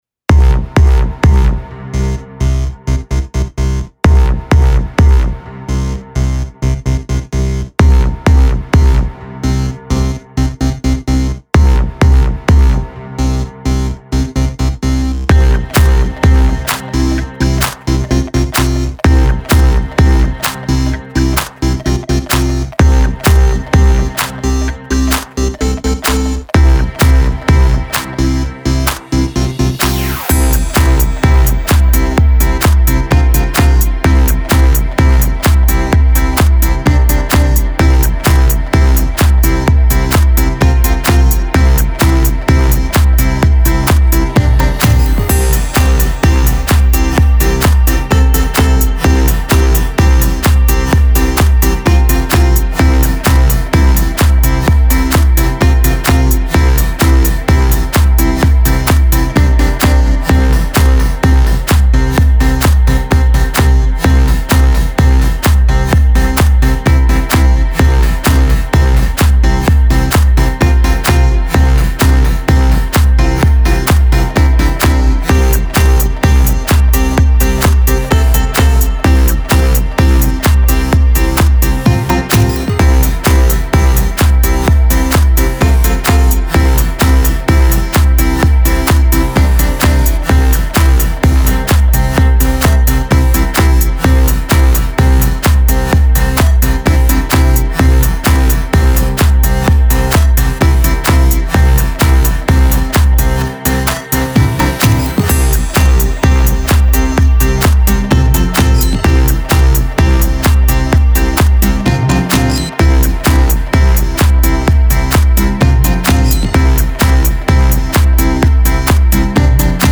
Ambient dance track
ambient
progressive
synth
positive
electro